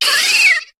Cri de Cabriolaine dans Pokémon HOME.